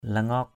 /la-ŋɔ:k/ (cv.) lingaok l{_z<K 1.